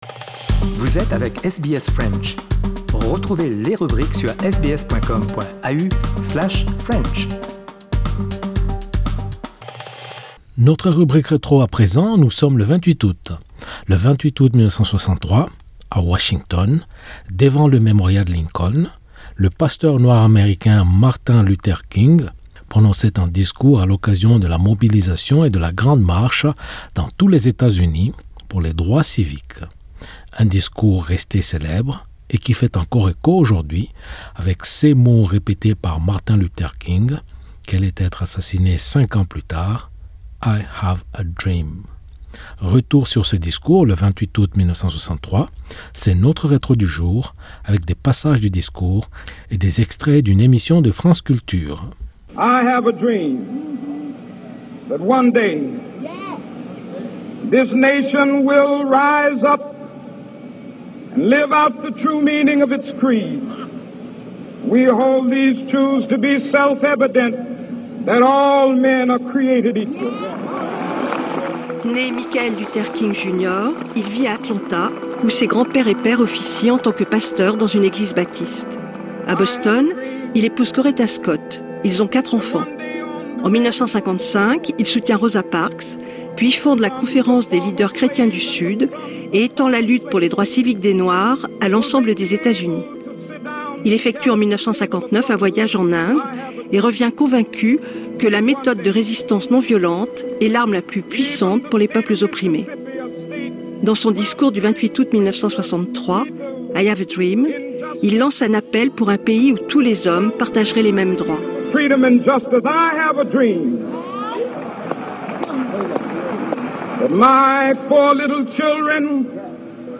Le 28 aout 1963... c'est notre retro du jour avec des passages du discours et des extraits d'une émission de France Culture...